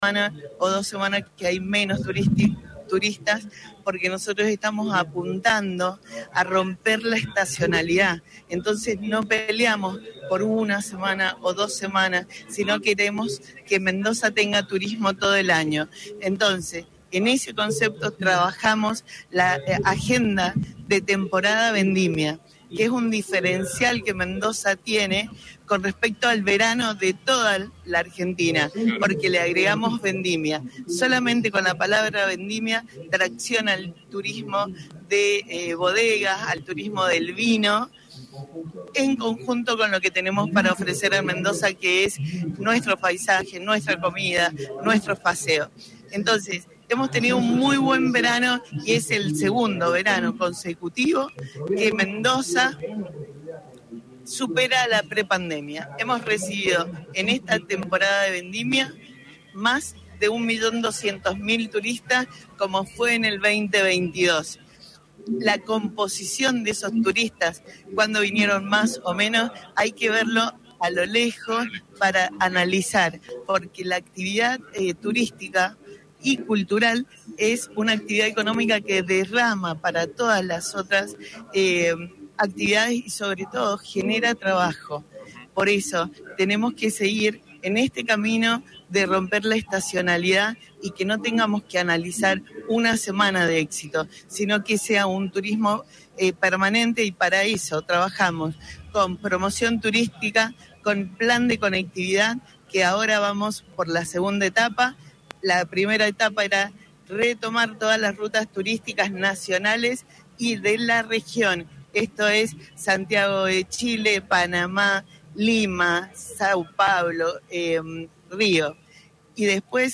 LVDiez - Radio de Cuyo - LVDiez desde Desayuno de COVIAR 2023
Nora Vicario, Ministra de Cultura y Turismo de Mendoza